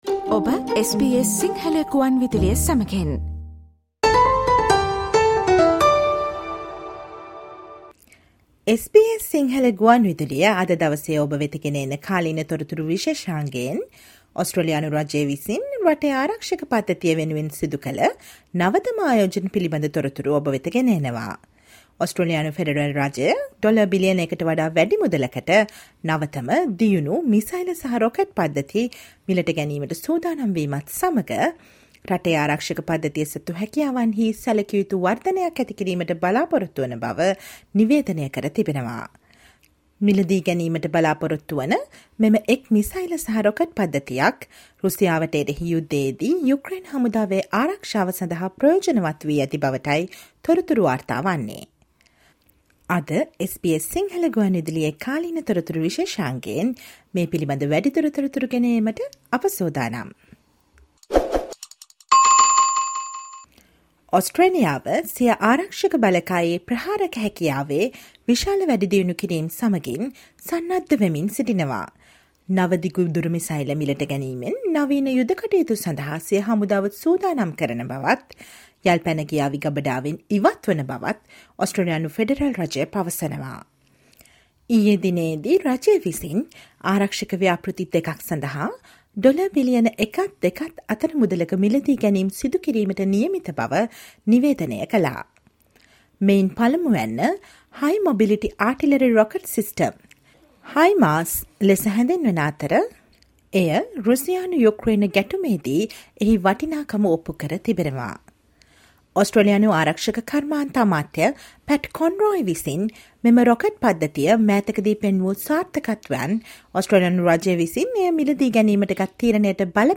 Listen to the SBS Sinhala Radio's current affairs feature on Friday, 06 January on the Federal Government's announcement of significant boost in its defense capabilities with a more than a billion dollar purchase of new advanced missile and rocket systems.